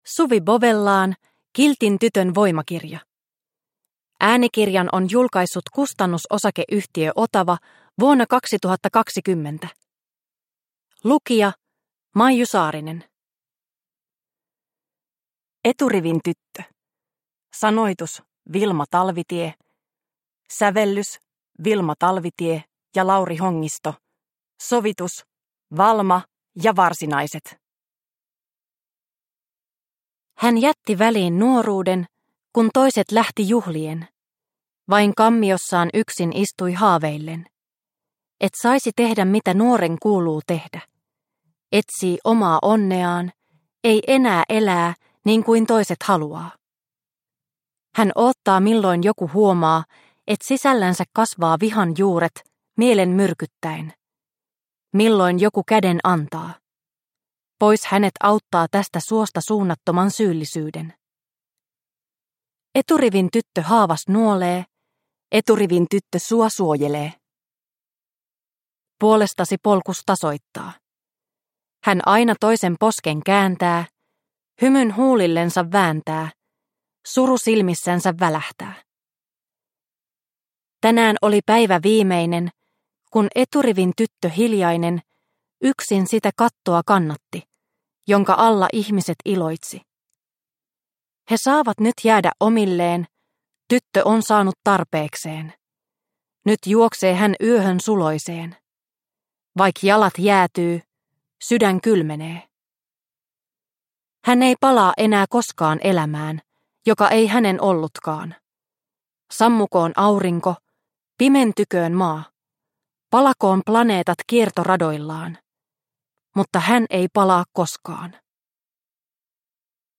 Kiltin tytön voimakirja – Ljudbok – Laddas ner